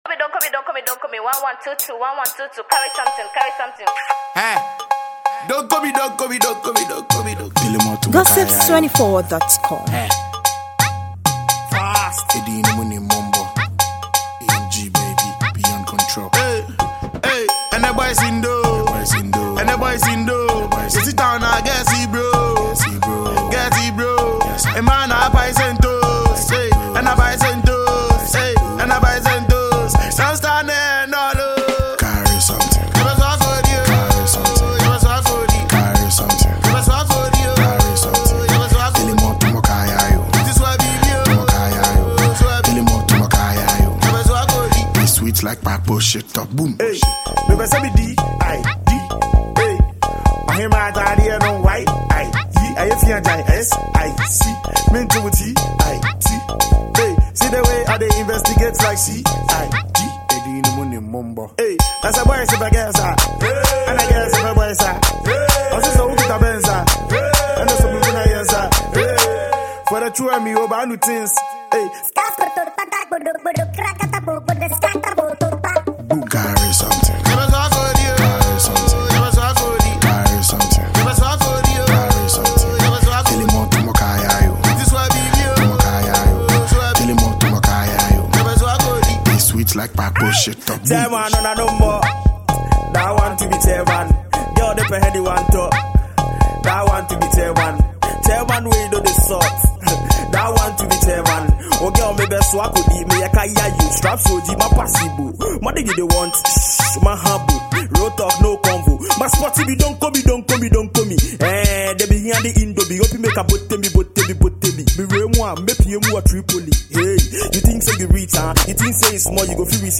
afrobeat single
fast-tempo potential street jam